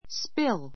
spíl ス ピ る